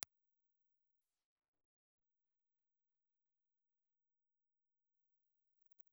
Ribbon
Cardioid
Impulse Response File:
Frequency response: 40 to 18 000 Hz ± 2.5 dB.